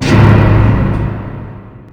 crash1.wav